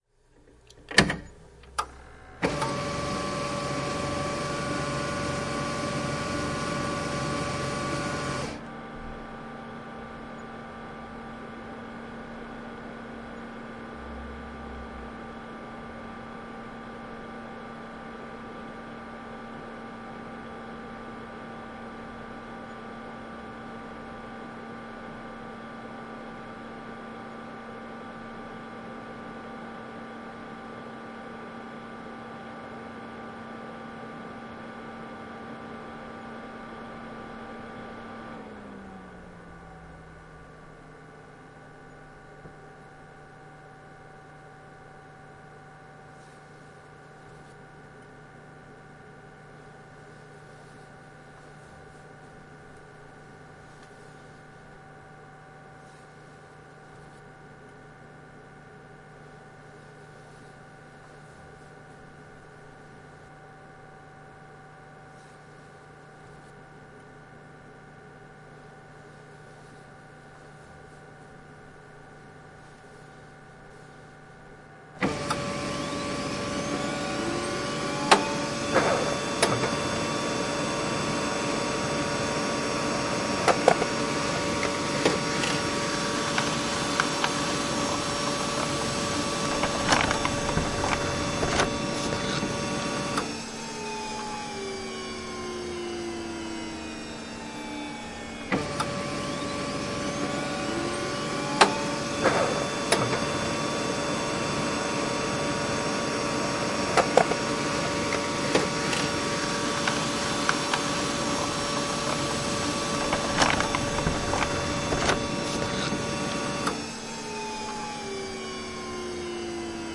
实际情况 " 惠普激光打印机2
描述：打开打印机然后等待它预热。打印机就绪暂停，打印两张纸，打印机就绪暂停最后关闭打印机关闭。可以编辑以扩展或删除操作。使用Roland R26 Omni＆amp; XY麦克风混合
Tag: 打印 - 单页 点击 开关关断状态 激光打印机 交换机上 办公室